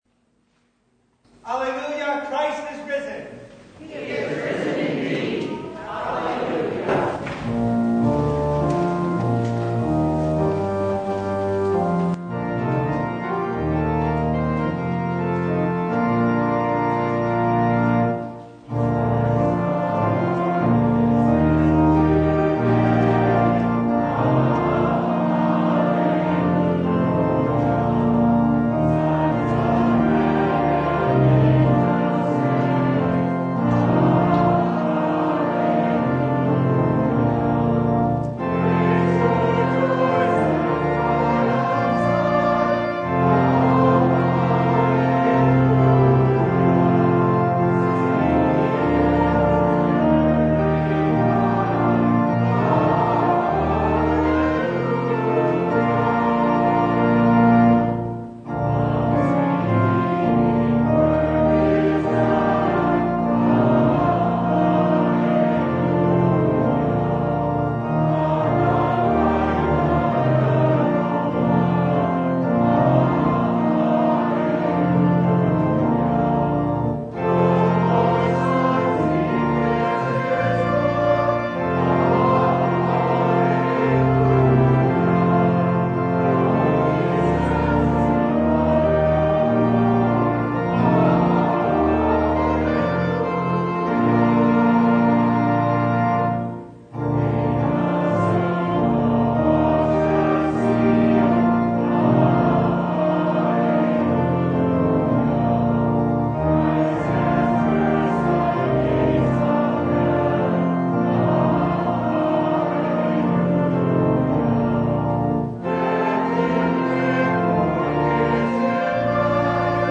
Mark 16:1-8 Service Type: Easter The women weren’t afraid of death